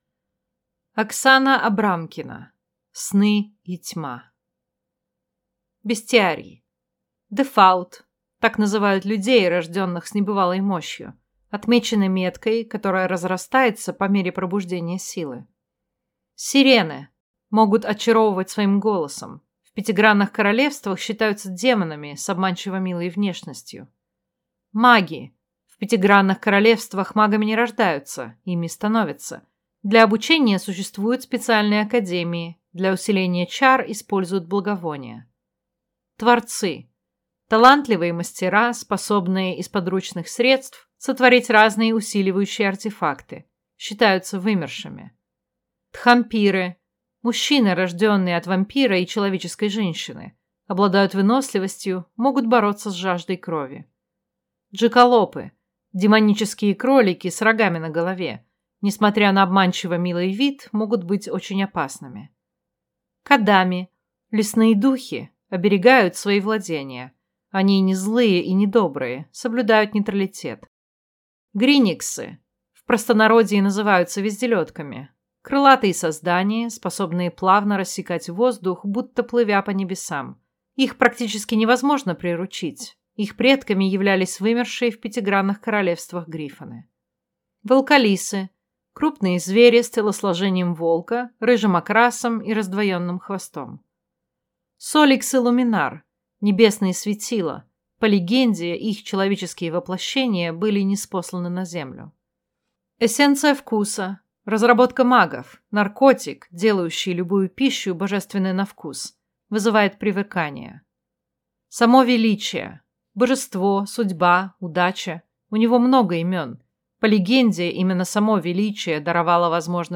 Аудиокнига Сны и тьма | Библиотека аудиокниг